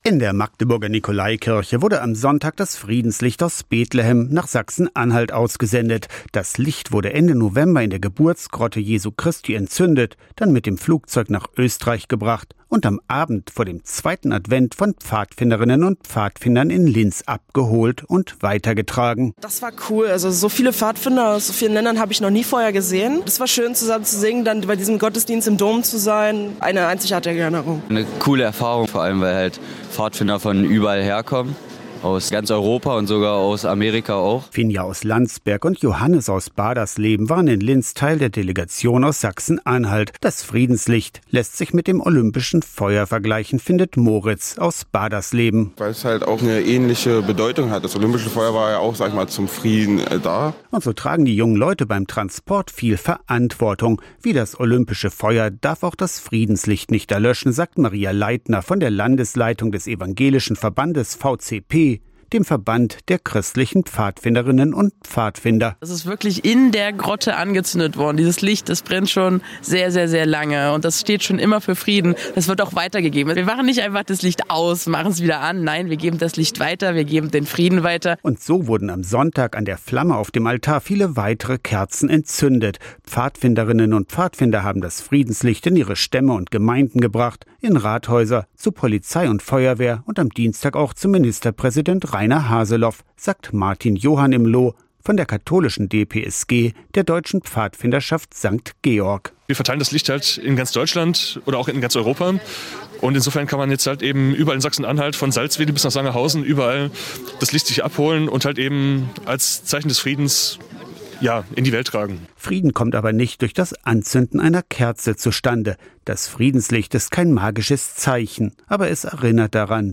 Aussendung des Friedenslichtes in der Nicolaikirche Magdeburg